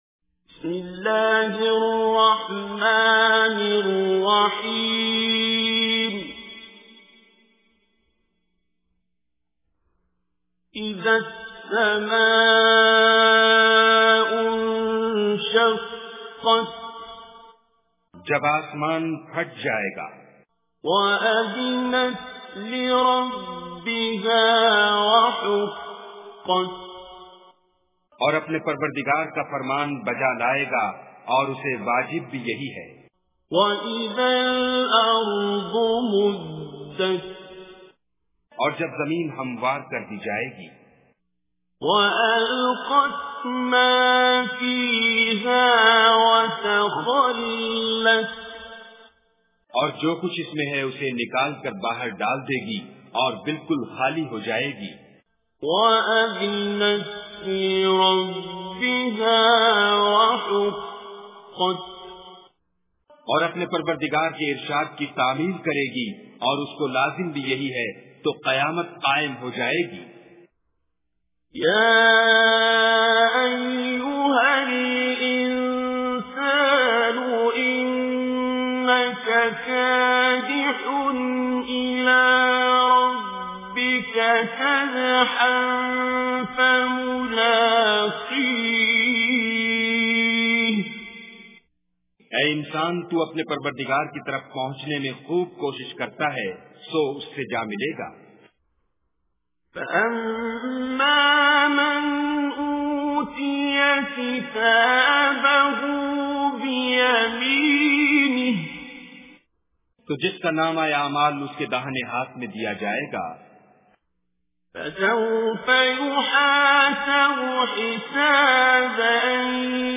Surah Inshiqaq Recitation with Urdu Translation